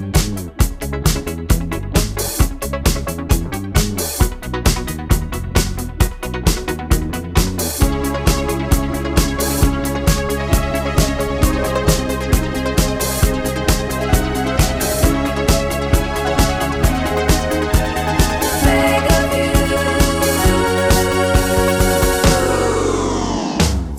One Semitone Down Pop (1970s) 4:27 Buy £1.50